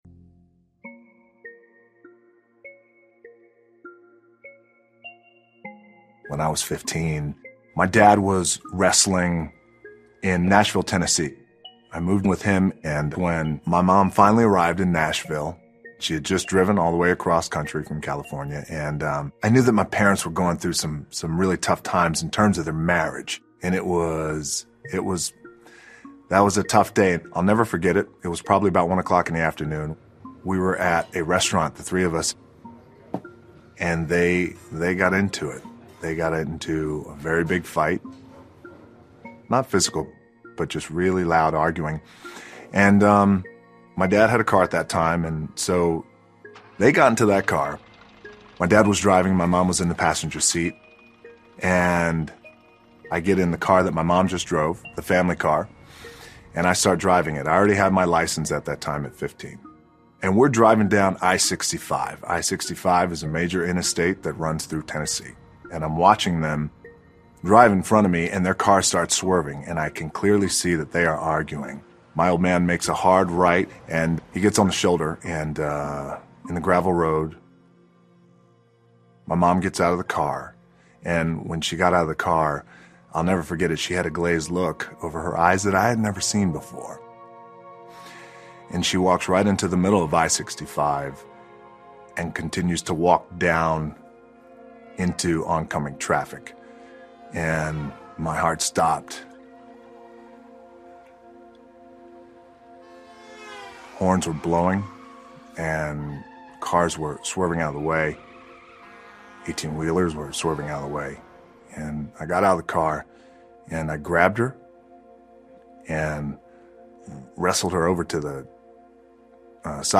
访谈录 硬汉影星“岩石”道恩·强森专访 听力文件下载—在线英语听力室